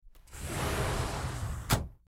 Glass Door Slides Open 03
Glass_door_slides_open_03.mp3